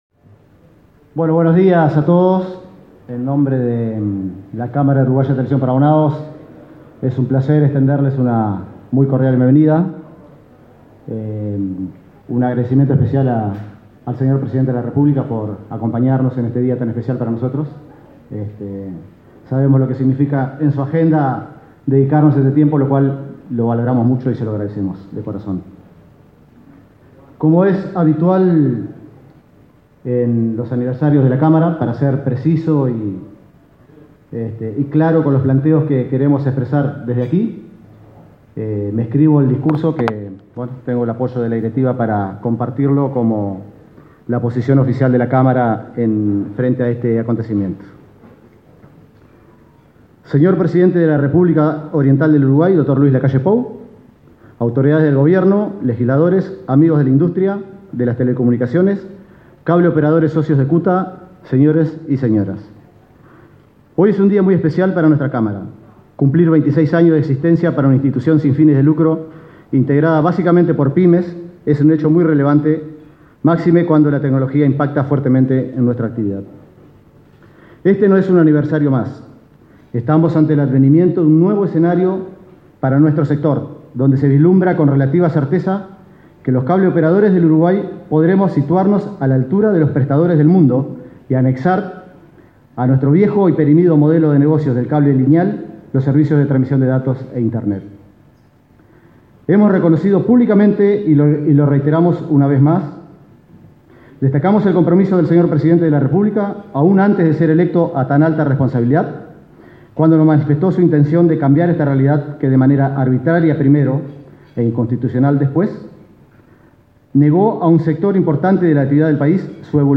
La Cámara Uruguaya de Televisión para Abonados (CUTA) celebró, este 11 de agosto, su 26.° aniversario, con la presencia del presidente de la República